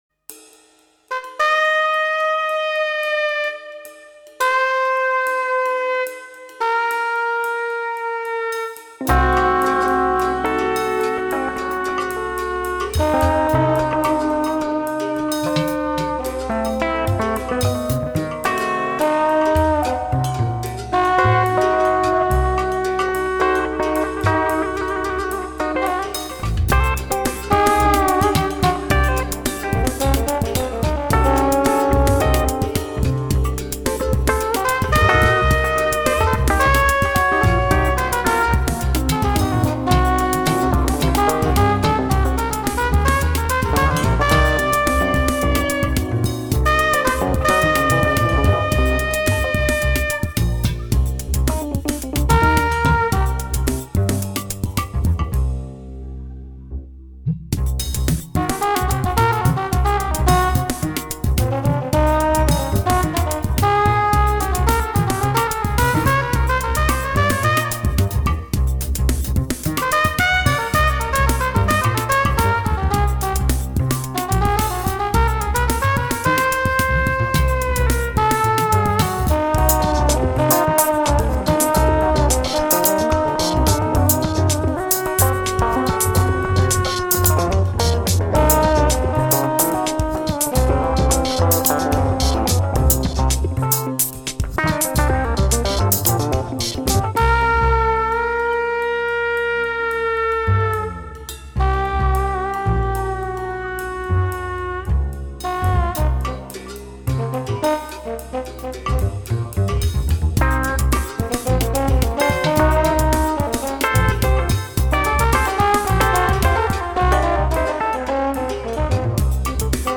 Funky, jazzy and loopy
a more urban, upbeat glimpse
guitars, imaginary horn, sampled electric piano,
keyboard double bass, loops and sequencing.